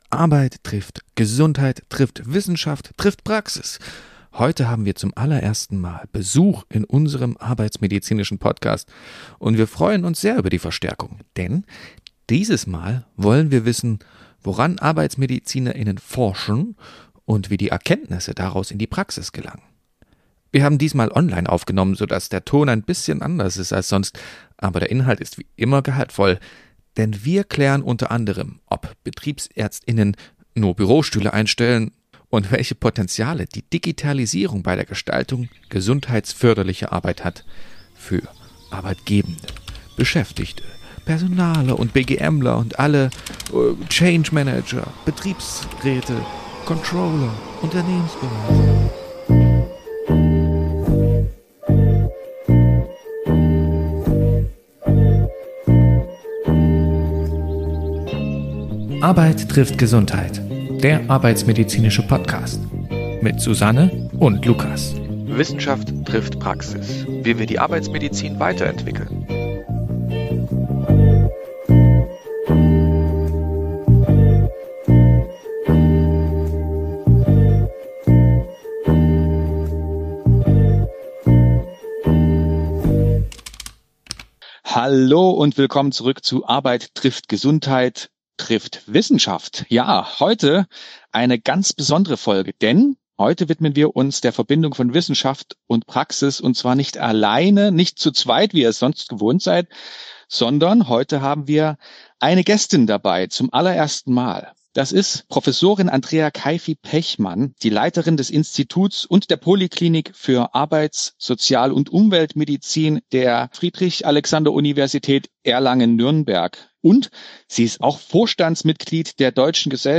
Wir sprechen darüber, wie die Digitalisierung neue Möglichkeiten in der betriebsärztlichen Versorgung eröffnet, welche Herausforderungen es insbesondere in kleineren Betrieben gibt und wie wissenschaftliche Erkenntnisse in den Alltag von Unternehmen gelangen. Zudem beleuchten wir die Kooperation der arbeitsmedizinischen Verbände unter dem Dach „Die Arbeitsmedizin“ und diskutieren, warum es so wichtig ist, dass das Fach mit einer Stimme auftritt. Entstanden ist ein Gespräch, das die Vielfalt und Relevanz der Arbeitsmedizin zeigt – und das nicht nur für Fachleute, sondern für alle, die sich für gesunde Arbeit und die Zukunft der medizinischen Versorgung interessieren.